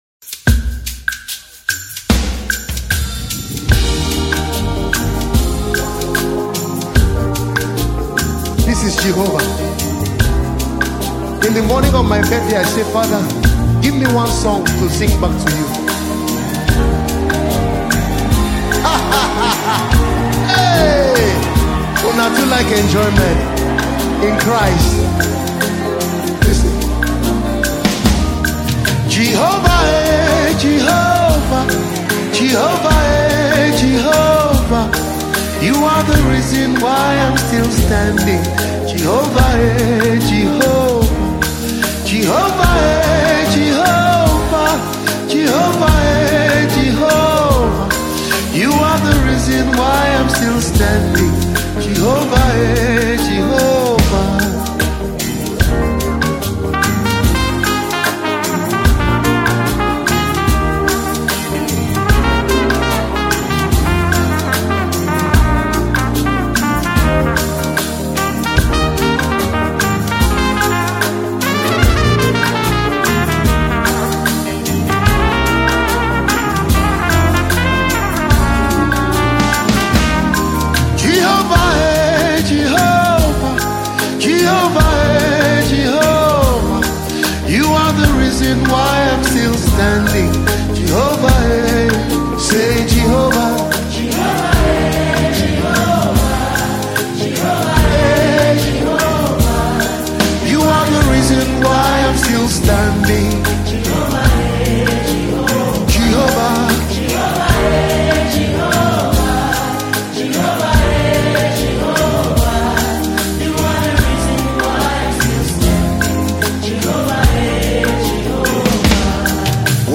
A top-notch Nigerian gospel musician
Through simple yet profound worship